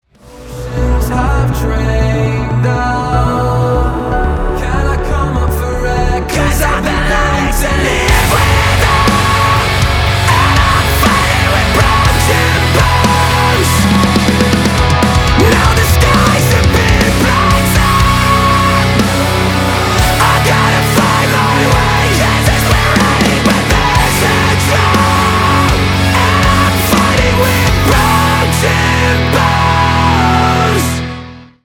громкие
мощные
брутальные
Metalcore
Post-Hardcore
гроулинг